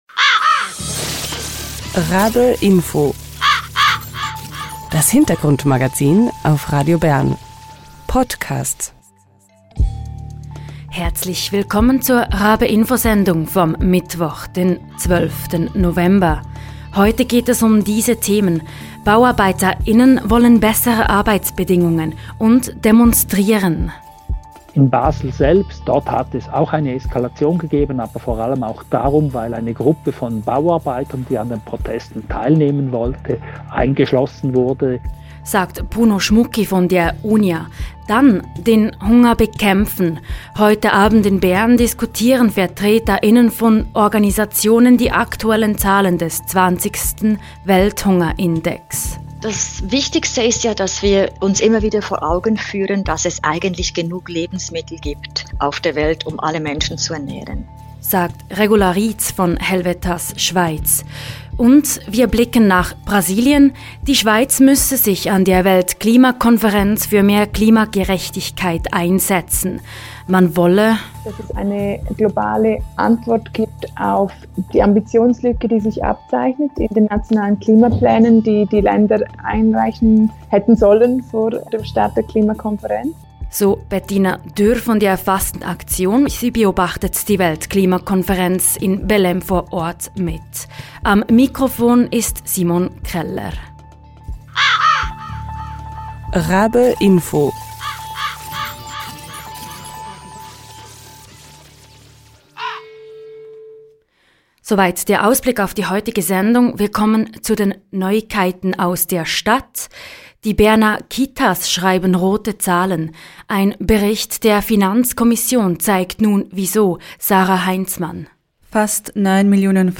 im Interview. Ausserdem: Am Montag startete im brasilianischen Belem die Weltklimakonferenz COP30.